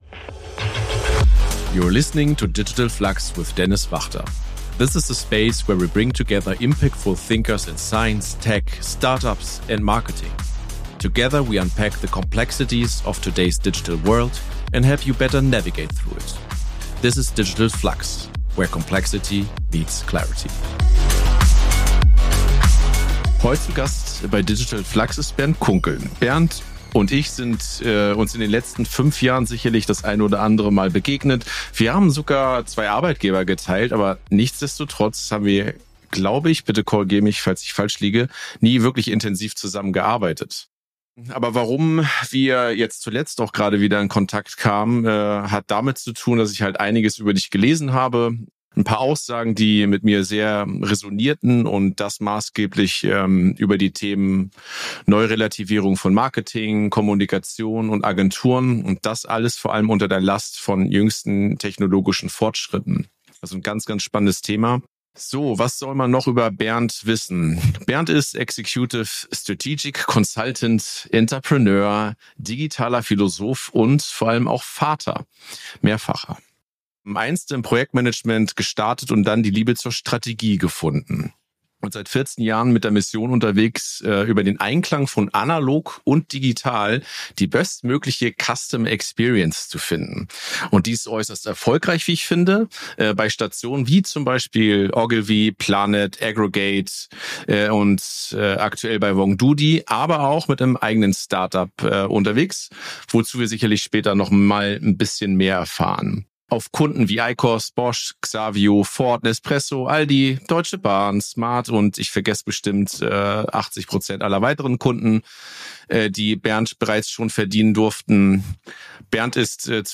interviews influential thinkers in science, tech, startups or marketing on some of the most important topics of the digital world.